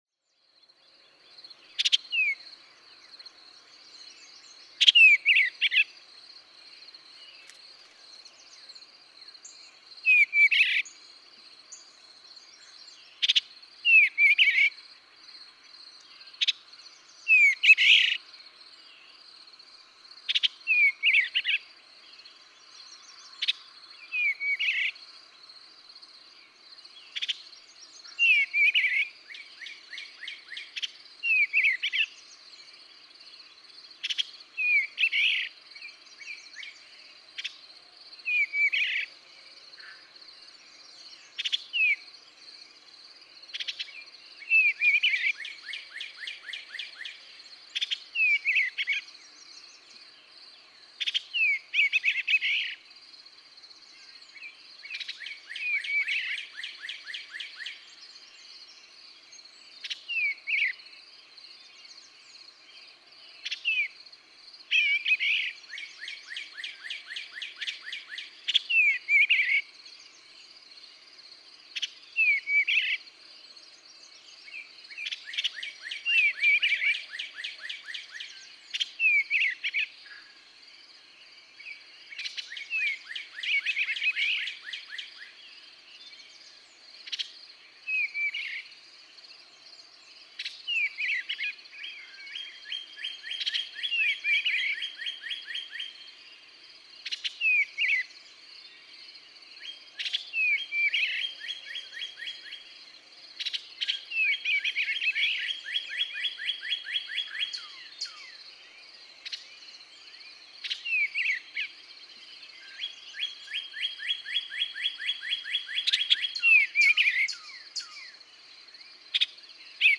Eastern bluebird
How he transforms at dawn! Songs are now delivered far more rapidly, and accompanied by a belligerent-sounding chatter. A male northern cardinal sings loudly in the background.
Amherst, Massachusetts.
480_Eastern_Bluebird.mp3